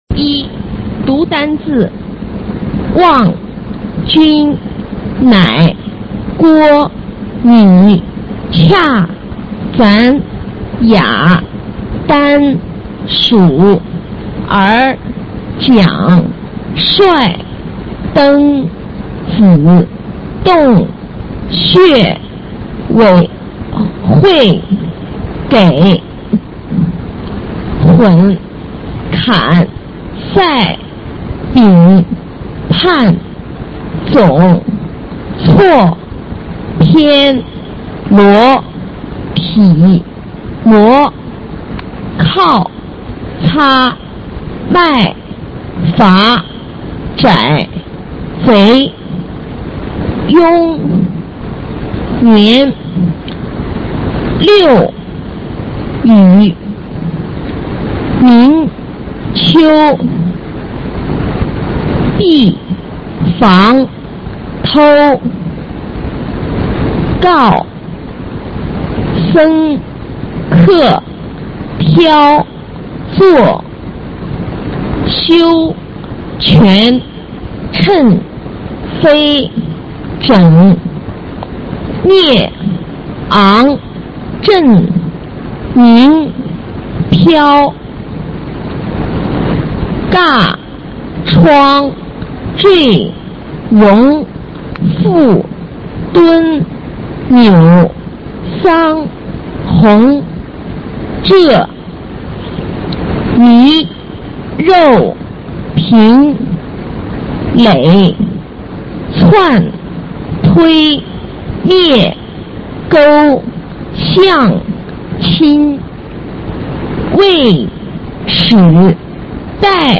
普通话水平测试二级甲等示范读音
三、朗读